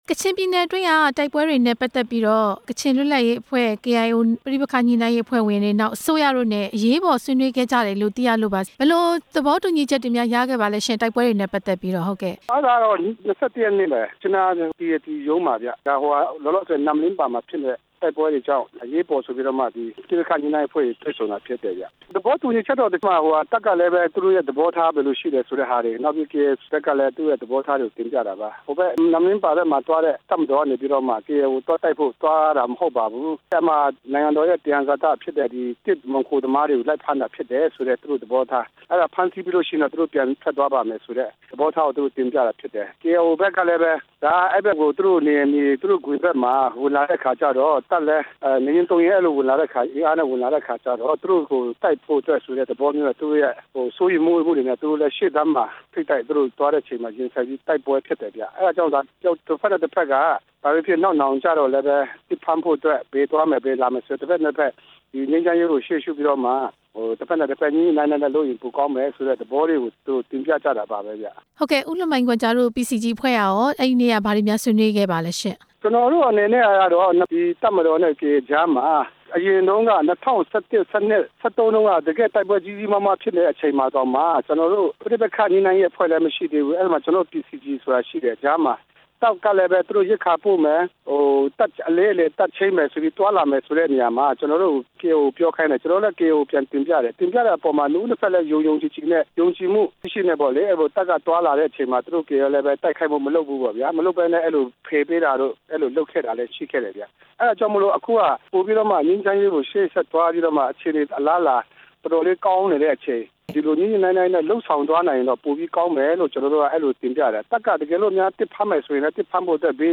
ကချင်ပြည်နယ်အတွင်း တိုက် ပွဲတွေ ပြင်းထန်နေတဲ့ အကြောင်းမေးမြန်းချက်